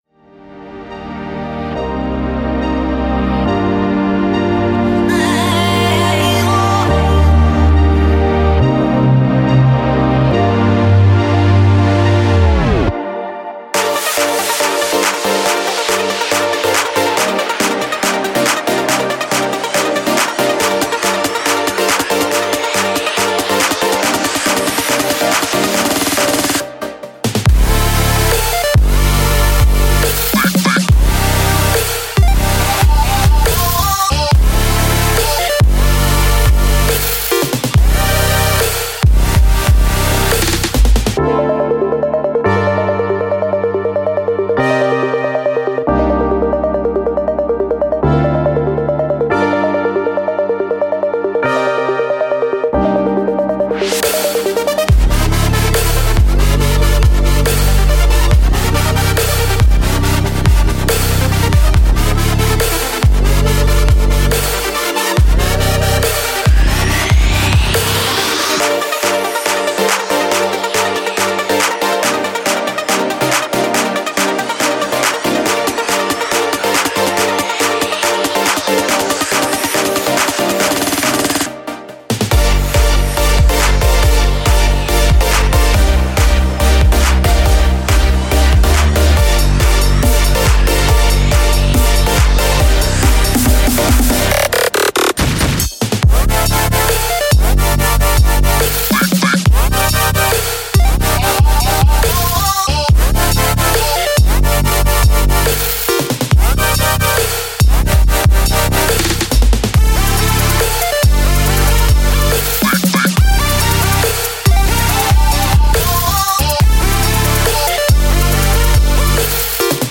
3. Future Bass